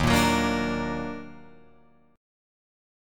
D#mM7 chord